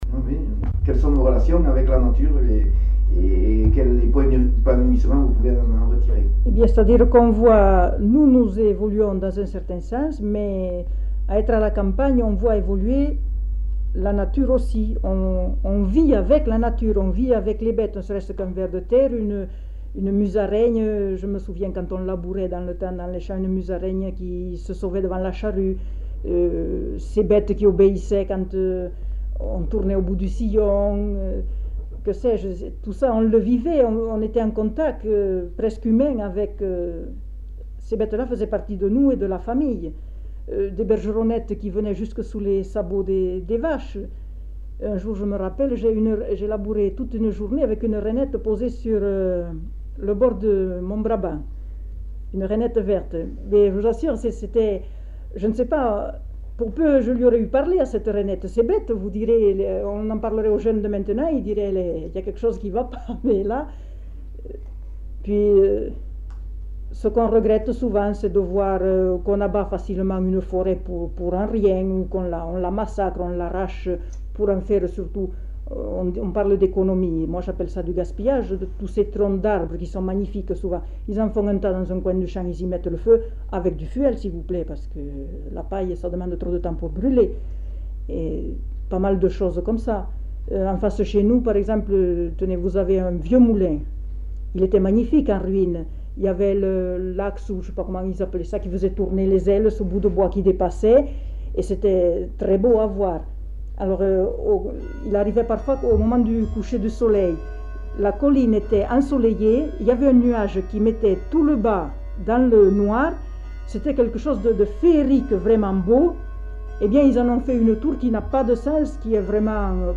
Aire culturelle : Savès
Lieu : Garravet
Genre : récit de vie